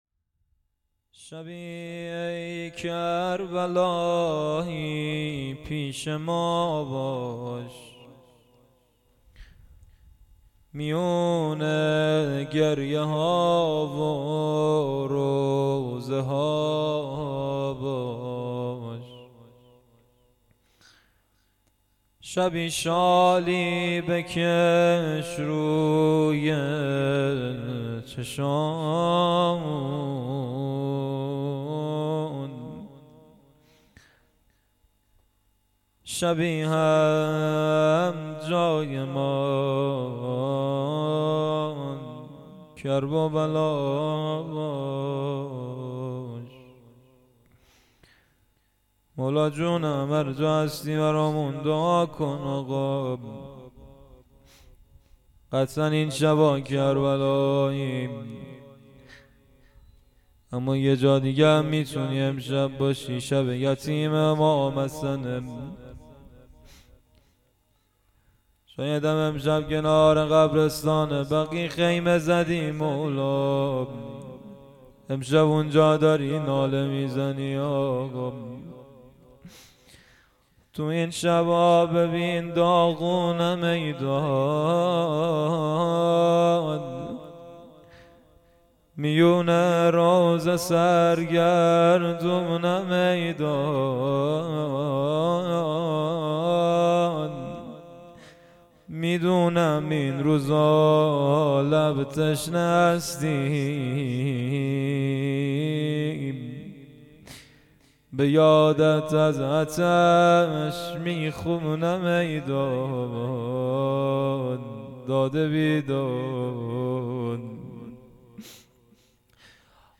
مراسم عزاداری دهه اول محرم الحرام 1399 - مسجد صاحب الزمان (عج) هرمزآباد